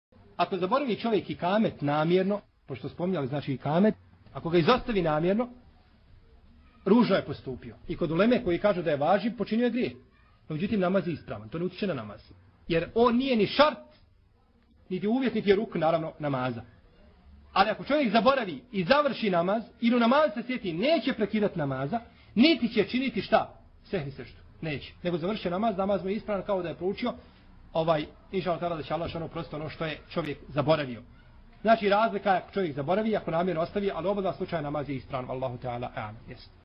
odgovor je na 44,45 minuti predavanja na linku ispod: